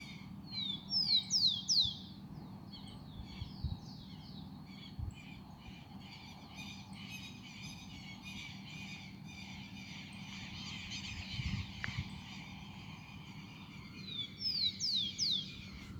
Tico-tico (Zonotrichia capensis)
Nome em Inglês: Rufous-collared Sparrow
Localidade ou área protegida: Concordia
Condição: Selvagem
Certeza: Observado, Gravado Vocal
Chingolo-1_1.mp3